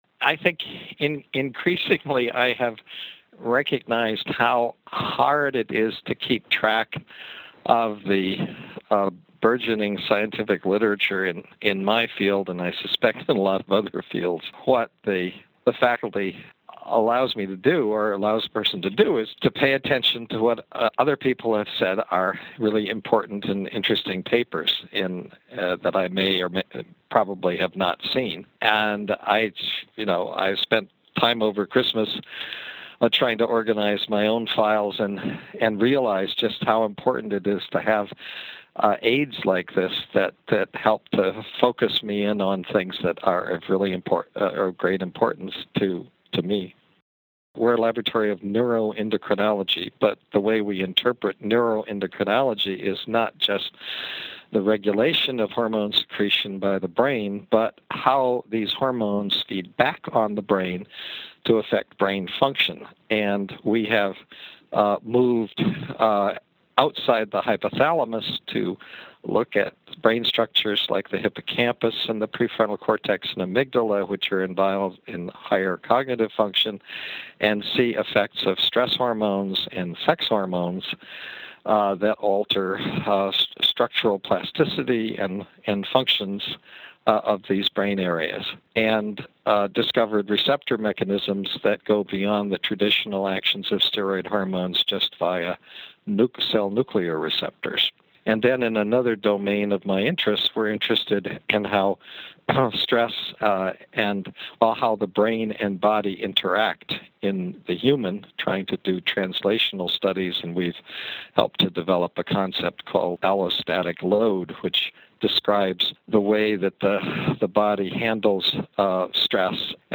I called Bruce and asked him why he likes F1000, and what he’s interested in.